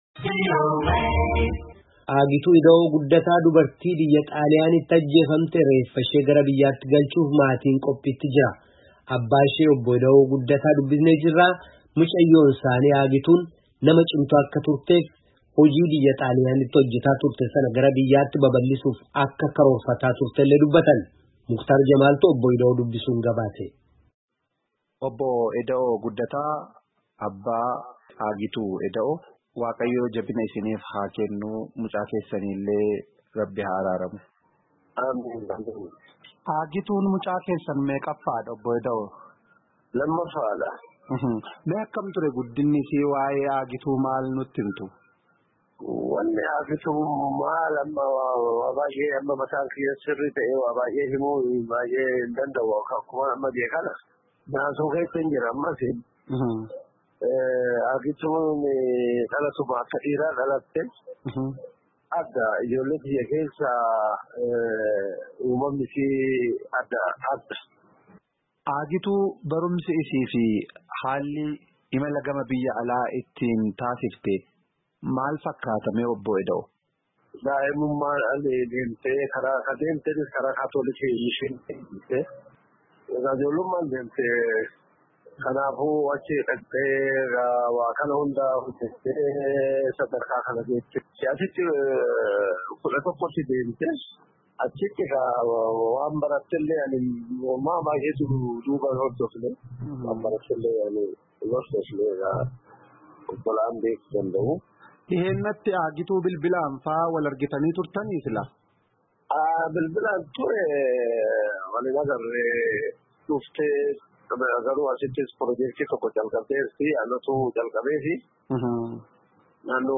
Gabaasaa guutuu caqasaa.